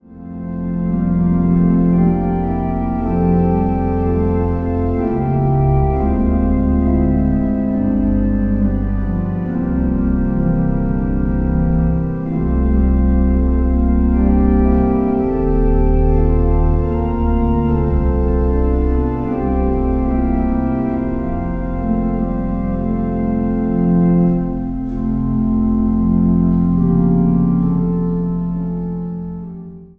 Zang | Gemengd koor